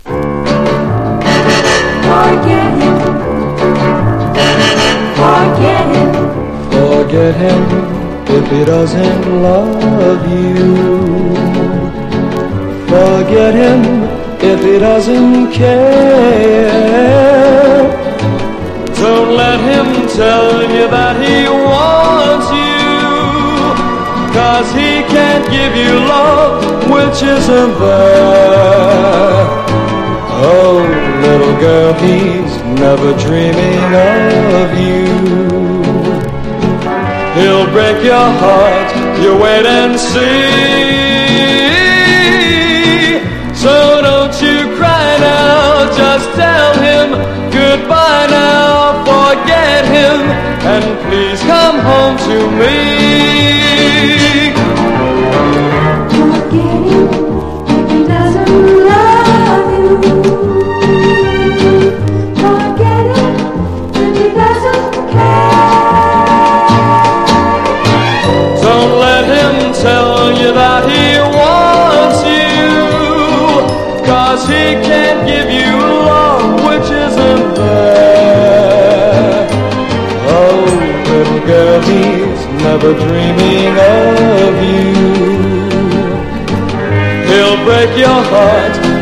1. 60'S ROCK >
# OLDIES / BLUES# VOCAL & POPS# 60’s ROCK